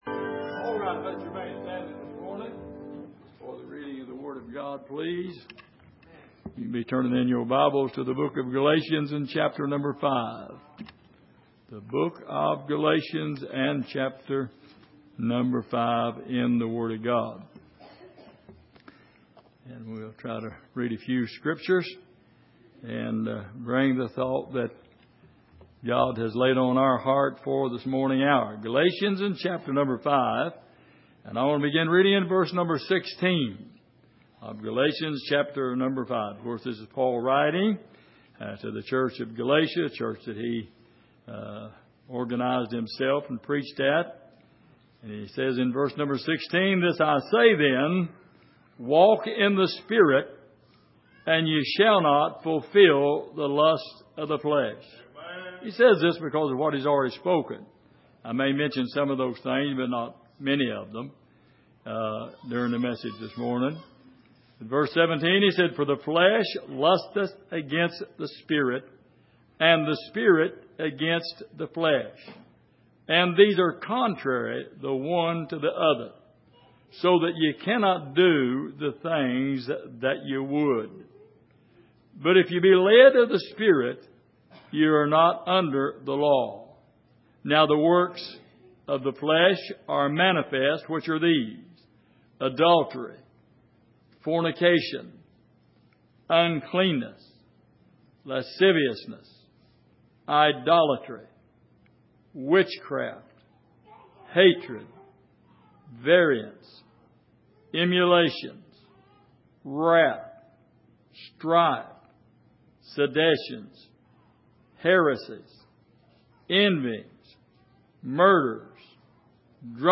Passage: Galatians 5:16-26 Service: Sunday Morning